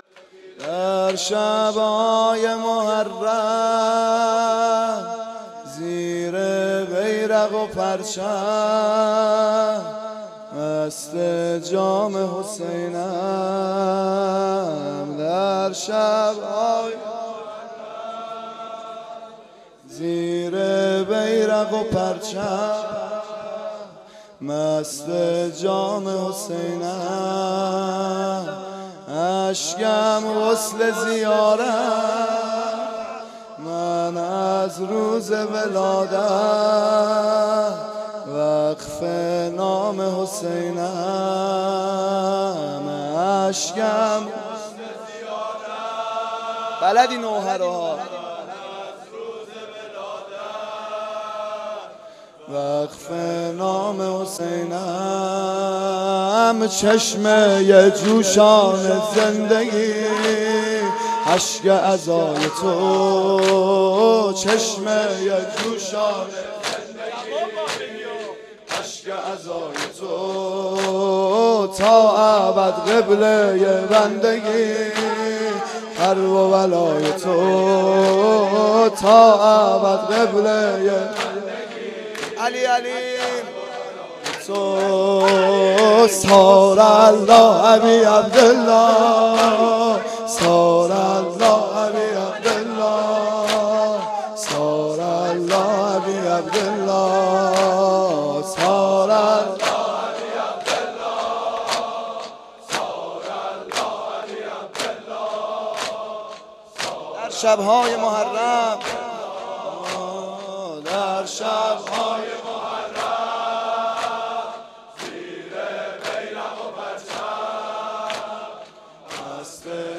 05asheghan-nohe8.mp3